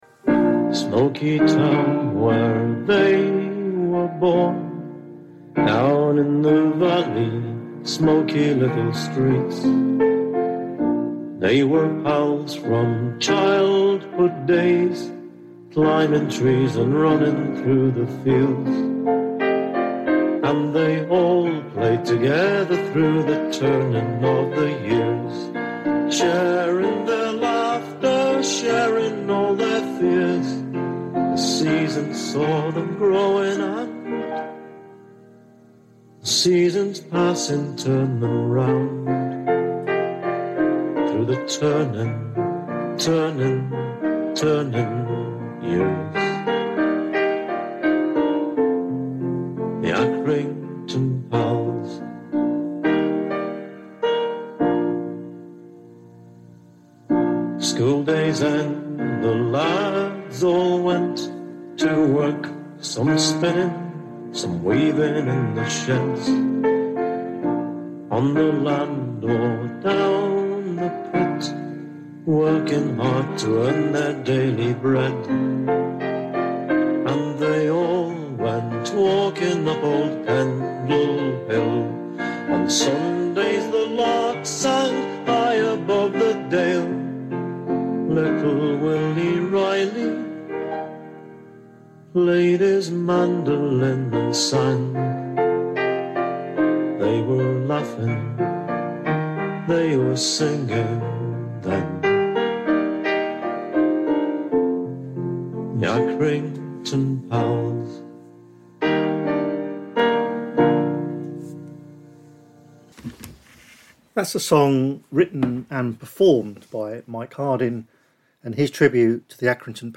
Facebook Twitter Headliner Embed Embed Code See more options In this episode we continue the story of the Accrington Pals at Serre on 1st July 1916. We listen to the music that was inspired by those young men and discover more of the stories of the lads from that Northern town on the 1st day of the Somme.